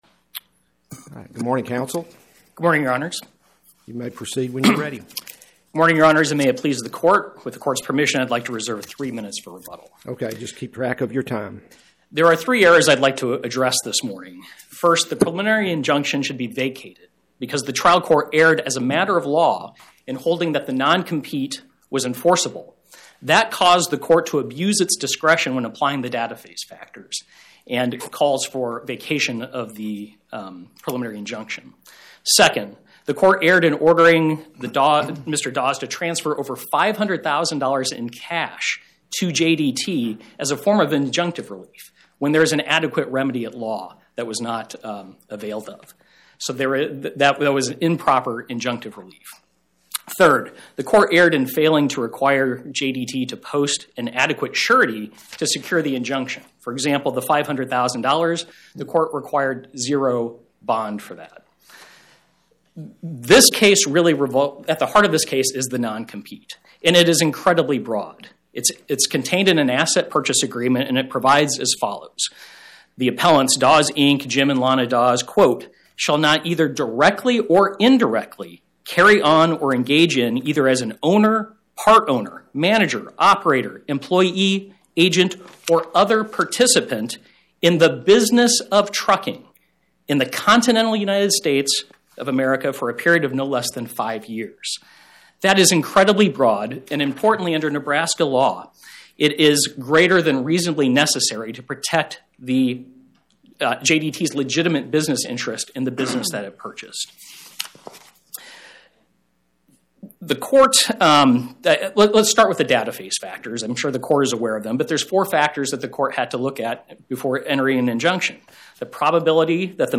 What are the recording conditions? My Sentiment & Notes 25-1915: Jim Daws Trucking, LLC vs Daws, Inc. Podcast: Oral Arguments from the Eighth Circuit U.S. Court of Appeals Published On: Thu Mar 19 2026 Description: Oral argument argued before the Eighth Circuit U.S. Court of Appeals on or about 03/19/2026